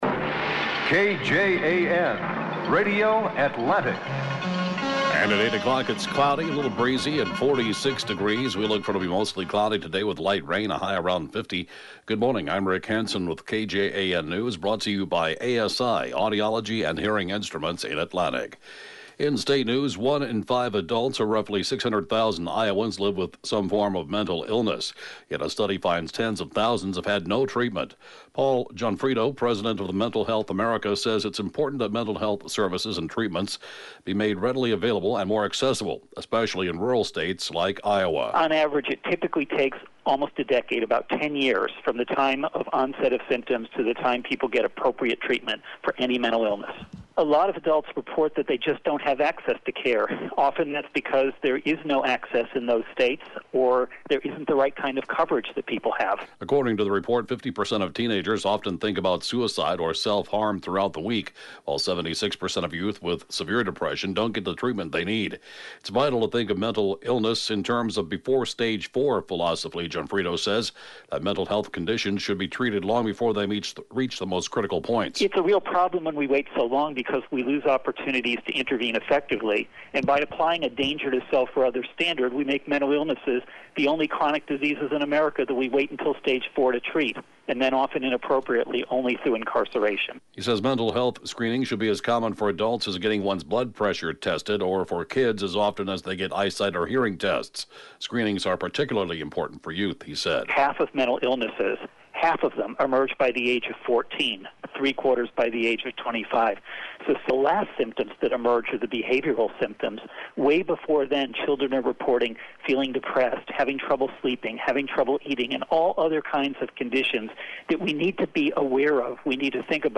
(Podcast) KJAN Morning News & Funeral report, 11/23/2018